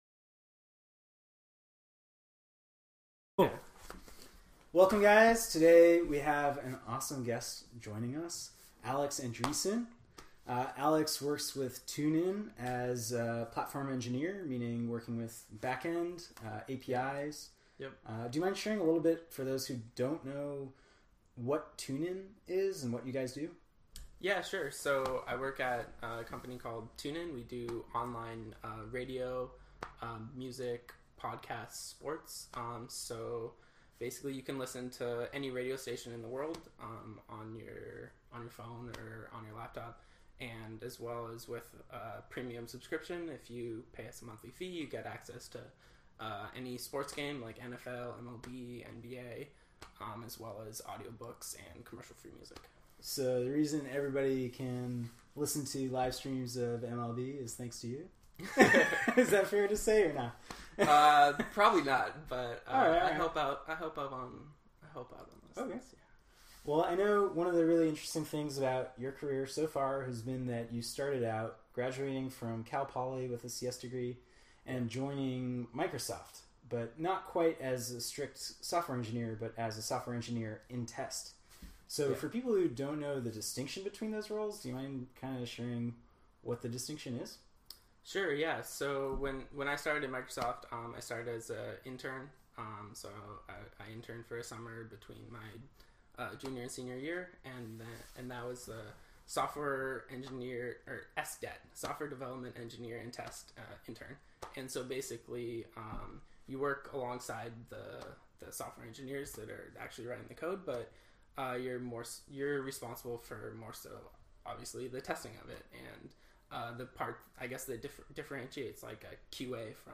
Microsoft to Startup: Leaving MegaCorp for Startupistan [INTERVIEW]